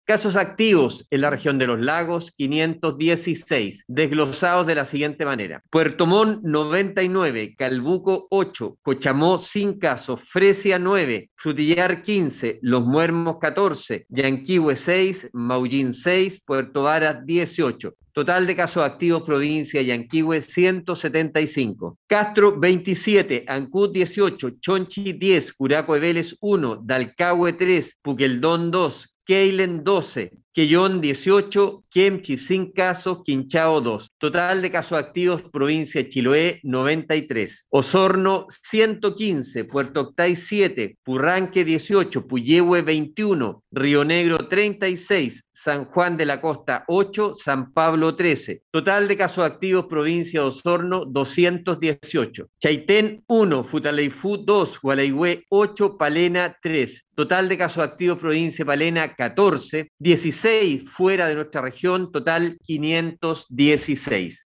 La autoridad de salud informó, además, acerca de los casos activos en la región: